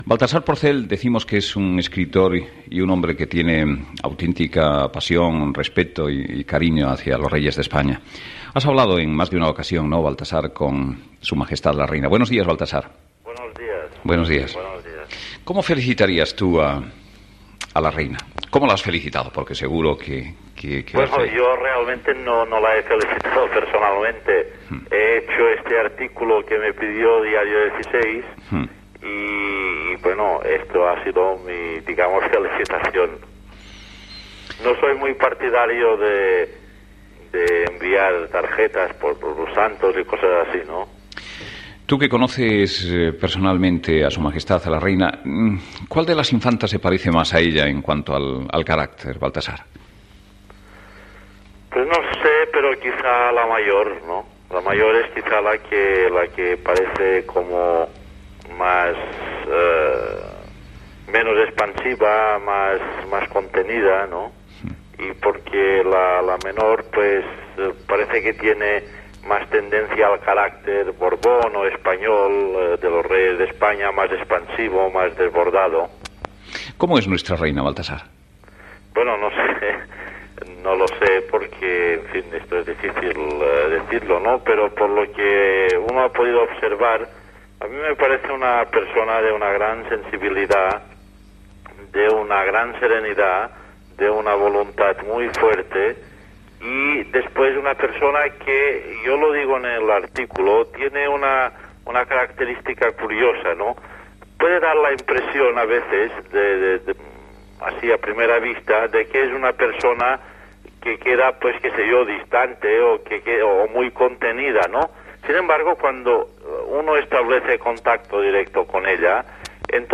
Entrevista a l'escriptor Baltasar Porcel sobre la Reina Sofia
Info-entreteniment